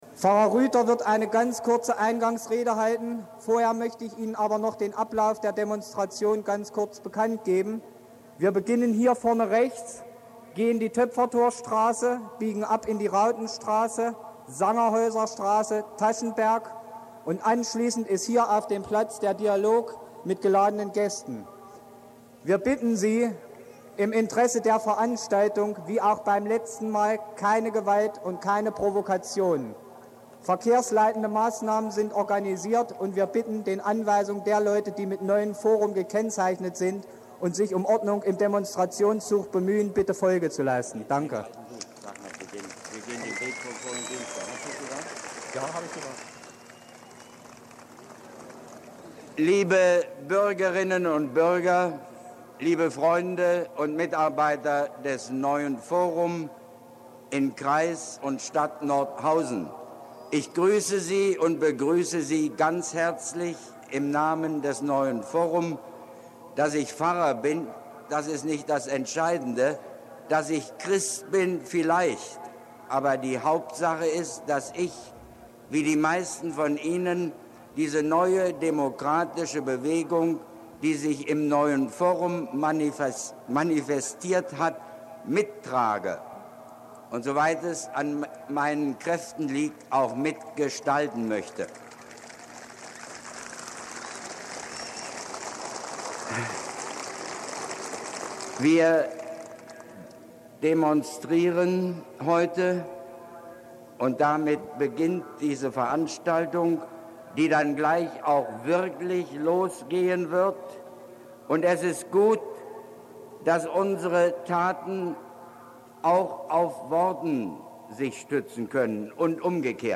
14.03.2020, 07:00 Uhr : Es waren historische Zeiten und emotionale Momente: Im Herbst des Jahres 1989 kamen zehntausende Menschen auf den Nordhäuser August-Bebel-Platz, um gegen die SED-Herrschaft zu demonstrieren. Der nnz-Redaktionen liegen einmalige Tonaufnahmen dieser Zeit vor...
Nicht montags, sondern dienstags riefen das Neue Forum und der Demokratische Aufbruch zu Demons und Umzügen in Nordhausen auf.